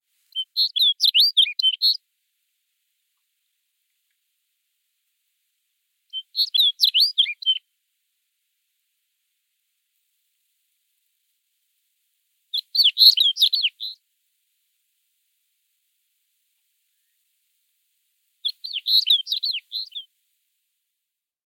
bruant-des-neiges.mp3